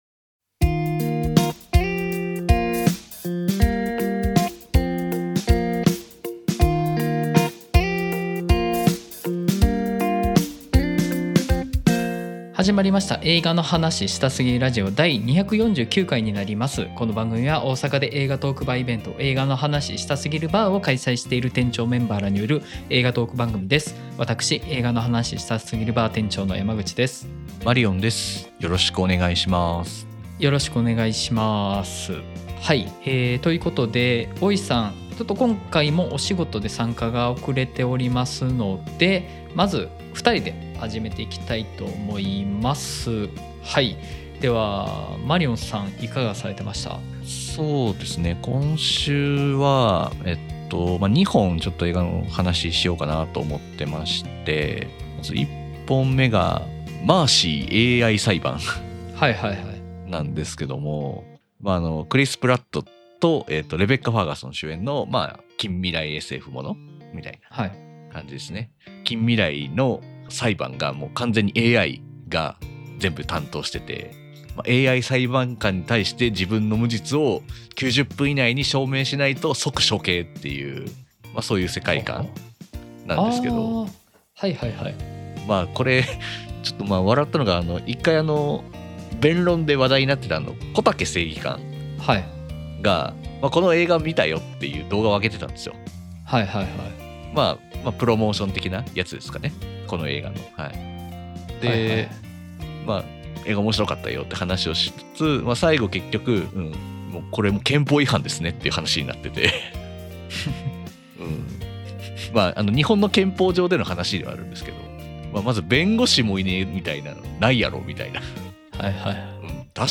※10/11 音質が悪すぎたため、可能な限りで音質改善しました。(でも全然音悪いです。)